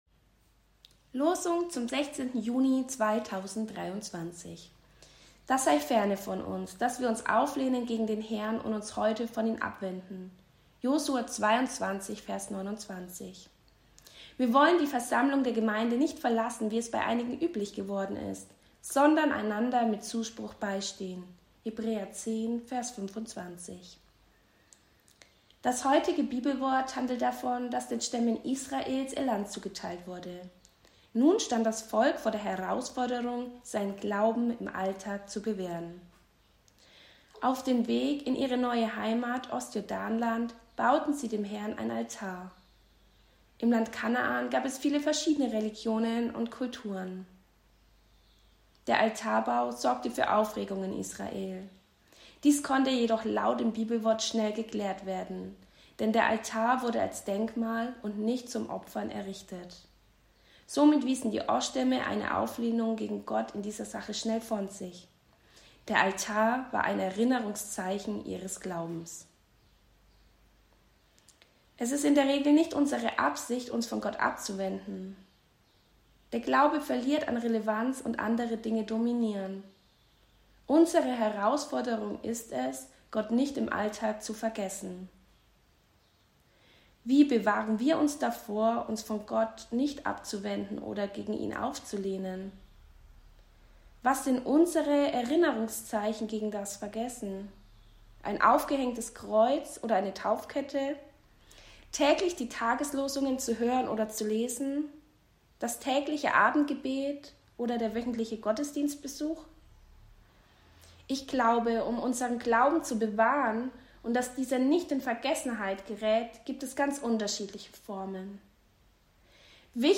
Impuls zur Tageslosung